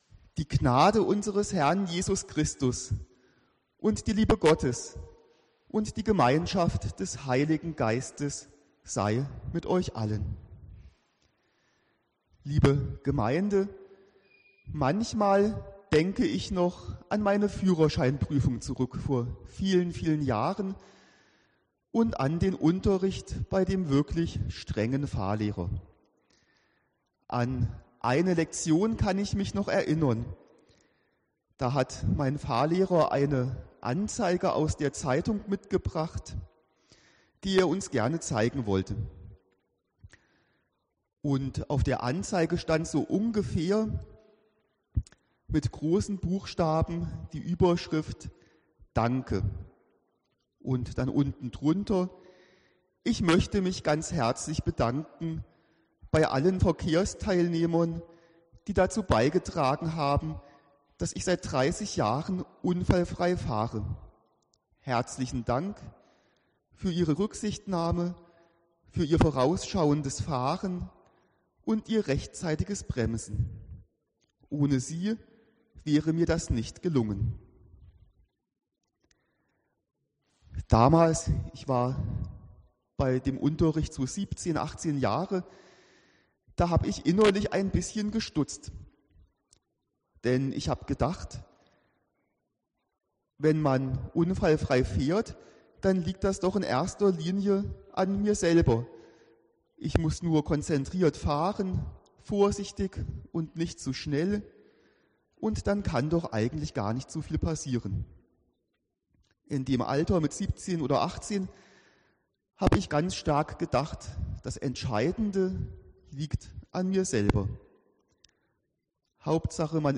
(Herbstfest) Predigt